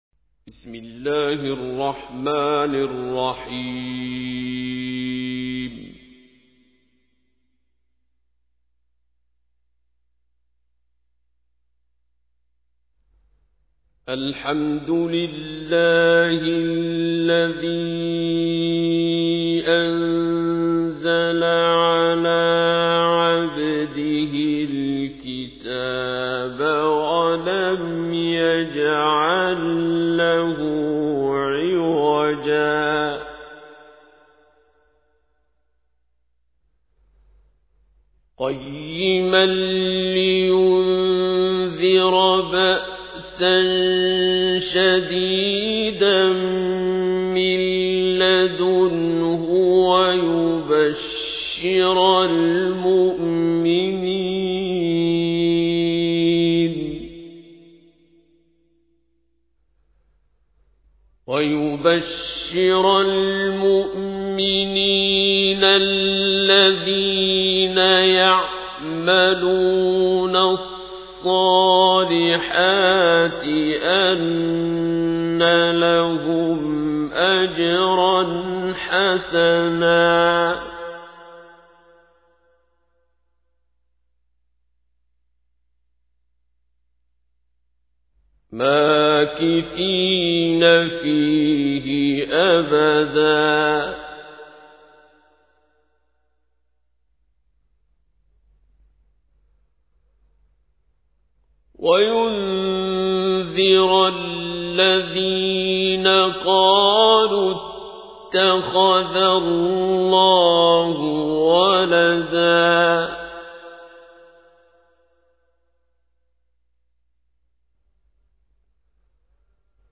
تحميل : 18. سورة الكهف / القارئ عبد الباسط عبد الصمد / القرآن الكريم / موقع يا حسين